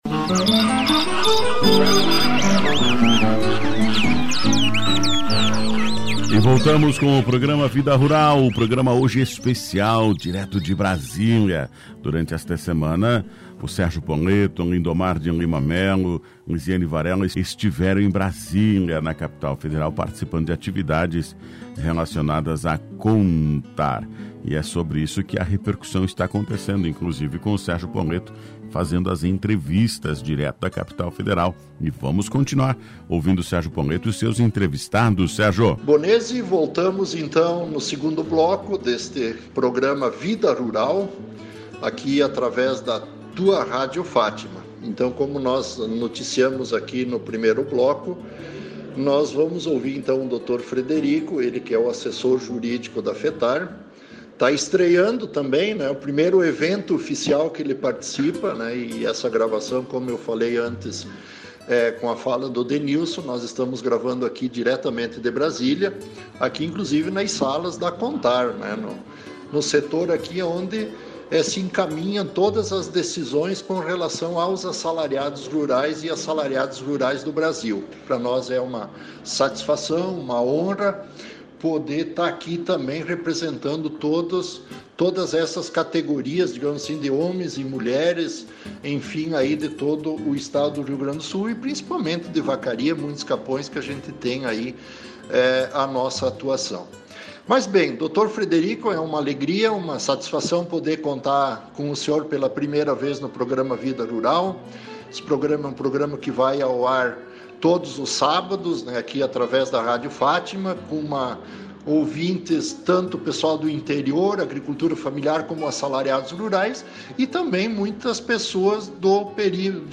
Programa Vida Rural, 09 de abril de 2022, edição especial, direto de Brasília